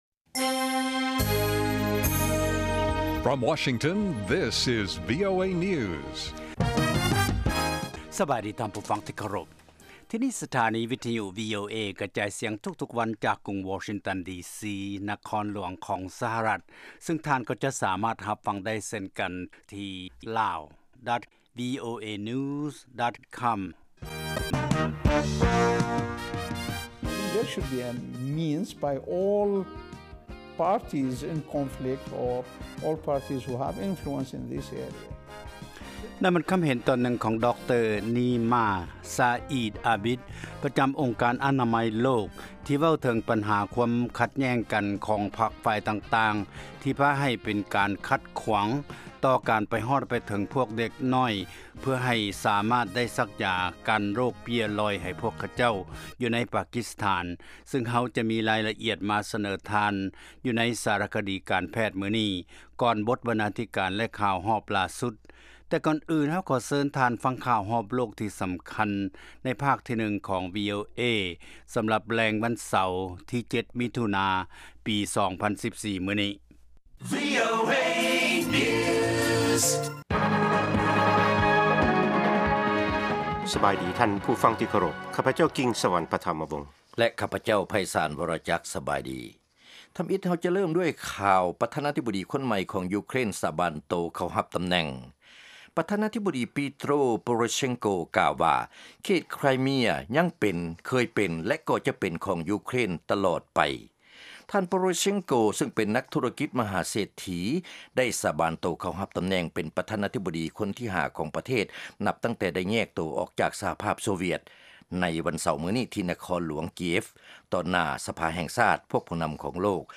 ລາຍການກະຈາຍສຽງຂອງວີໂອເອ ລາວ
ວີໂອເອພາກພາສາລາວ ກະຈາຍສຽງທຸກໆວັນ ເປັນເວລາ 30 ນາທີ.